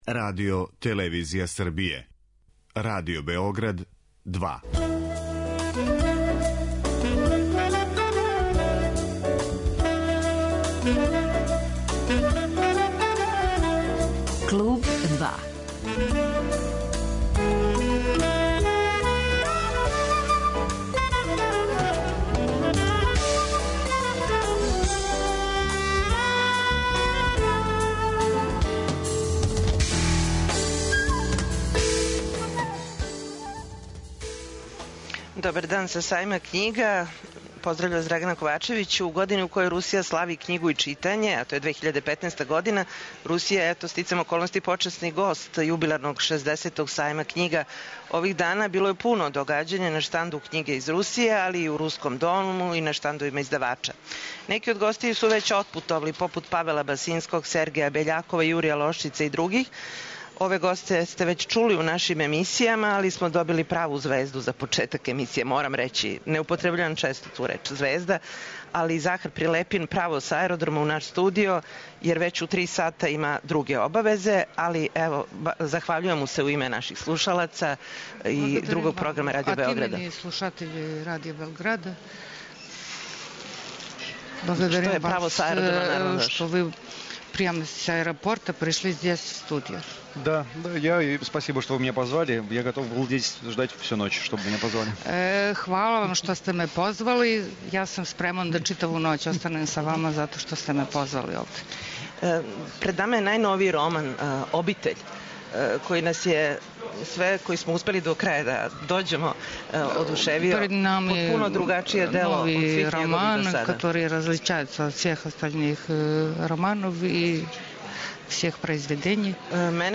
Директно са Сајма књига